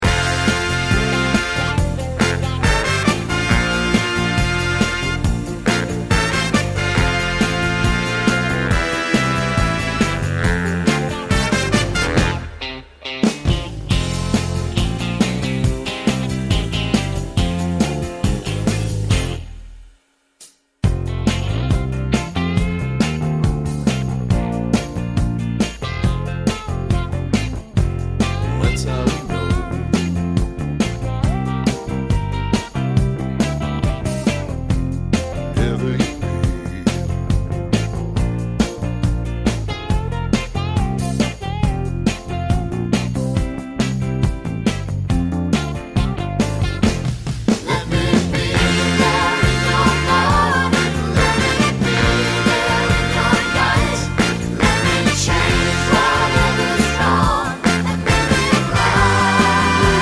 Live Version Pop (1960s) 3:33 Buy £1.50